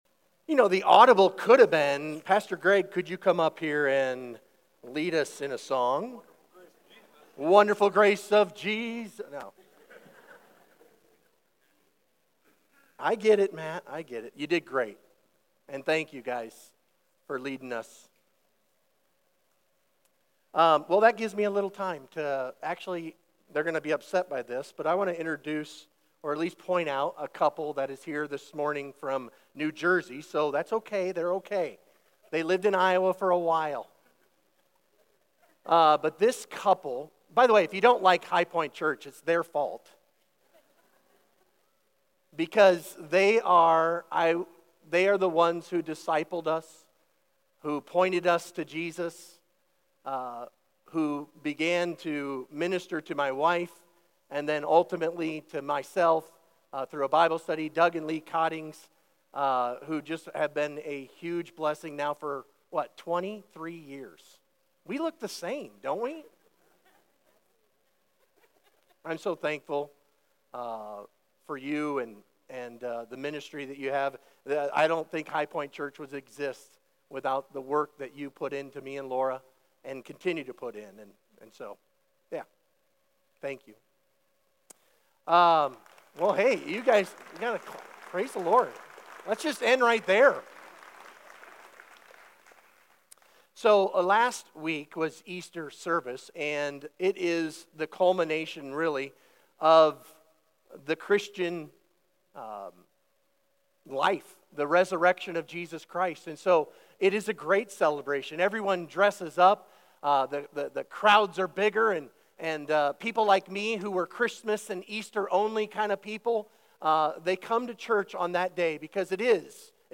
Sermon Questions Read Luke 24:13-35.